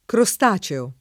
[ kro S t #© eo ]